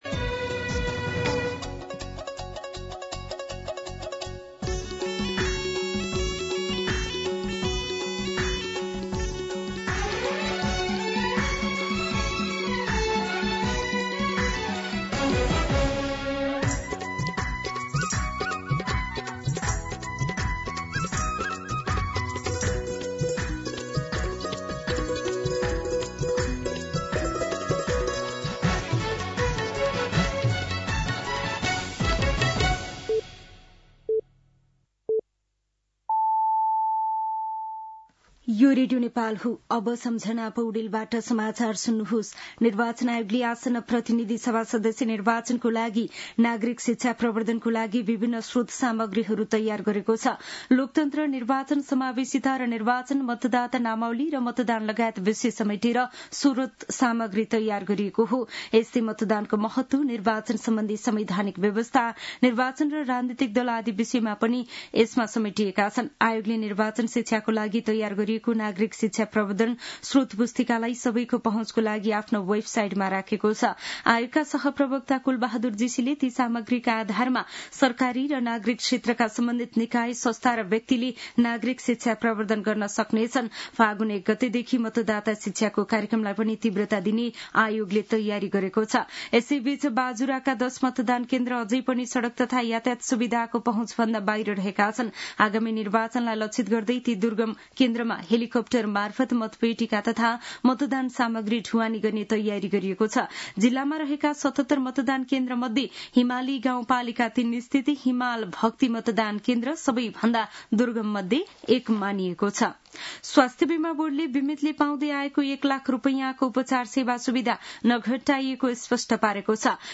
दिउँसो १ बजेको नेपाली समाचार : २९ माघ , २०८२